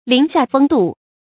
林下風度 注音： ㄌㄧㄣˊ ㄒㄧㄚˋ ㄈㄥ ㄉㄨˋ 讀音讀法： 意思解釋： 稱頌婦女嫻雅飄逸的風采 出處典故： 沈從文《菜園》：「母親還是同樣的不失 林下風度 。」